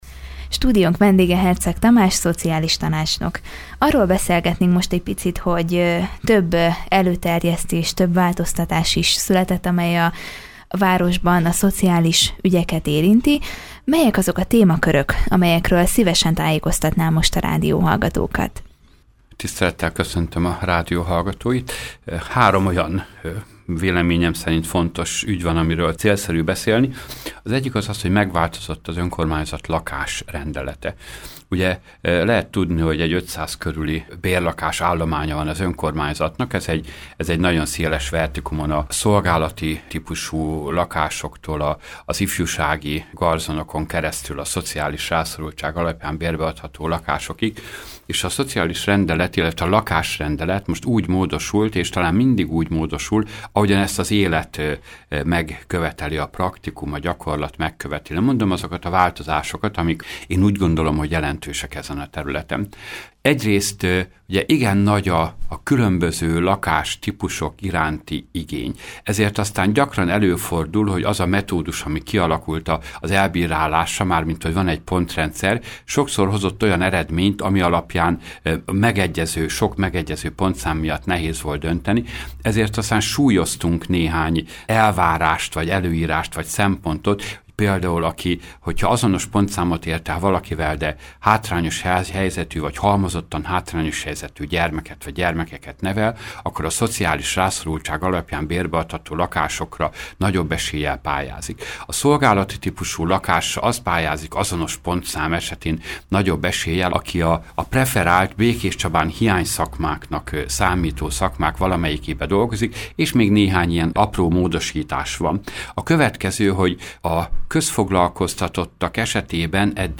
Több változás is történt a szociális ügyekben Békéscsabán. Ezzel kapcsolatban beszélgetett tudósítónk Herczeg Tamás tanácsnokkal.